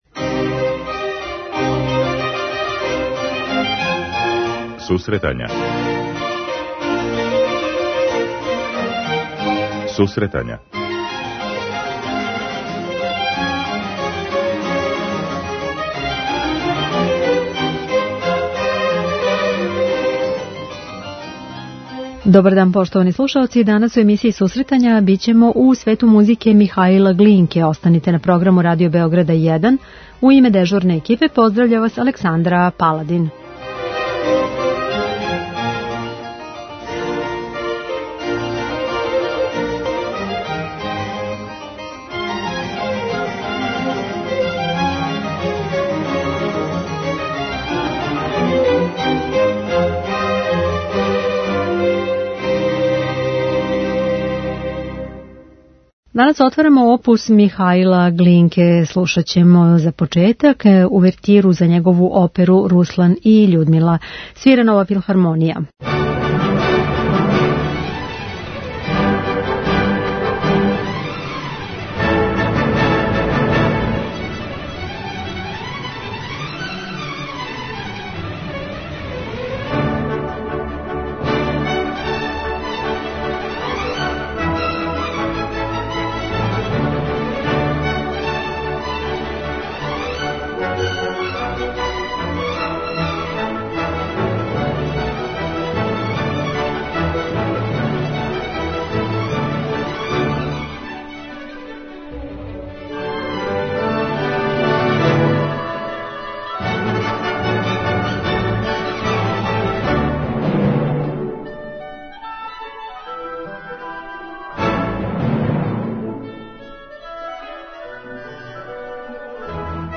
Михаил Глинка је један од најзначајнијих руских композитора 19. века, и сматра се творцем руског националног стила у музици. Из опуса овог аутора данас ће бити емитована нека од најзначајнијих дела, међу којима су одломци из опера 'Руслан и Људмила' и 'Иван Сусањин' и симфонијска композиција 'Камаринскаја'.
преузми : 10.14 MB Сусретања Autor: Музичка редакција Емисија за оне који воле уметничку музику.